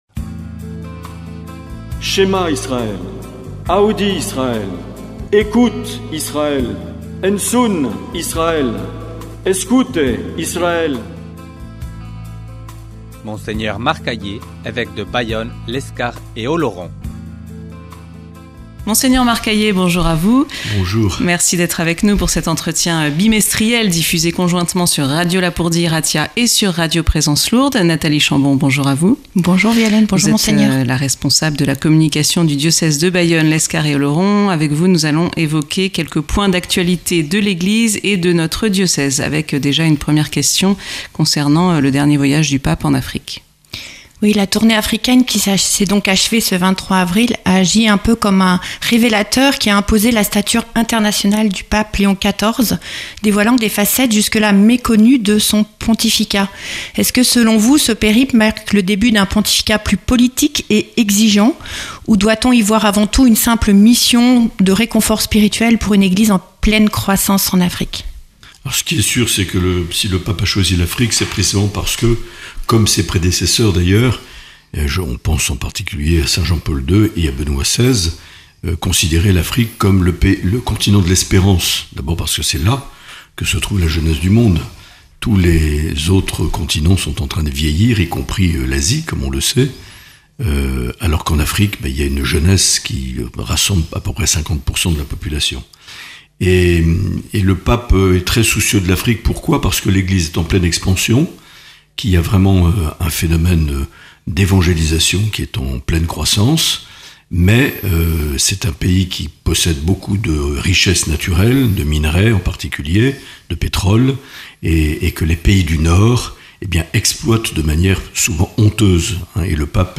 L’entretien avec Mgr Marc Aillet - Avril 2026